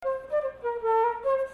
Tune